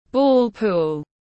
Ball pool /ˈbɔːl ˌpuːl/